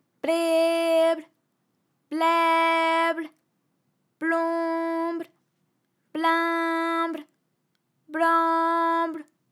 ALYS-DB-001-FRA - First, previously private, UTAU French vocal library of ALYS
blehblaiblonblinblanbl.wav